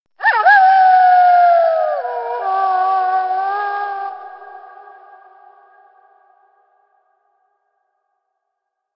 دانلود آهنگ زوزه گرگ در زمستان از افکت صوتی انسان و موجودات زنده
دانلود صدای زوزه گرگ از ساعد نیوز با لینک مستقیم و کیفیت بالا
جلوه های صوتی